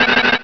Cri de Terhal dans Pokémon Rubis et Saphir.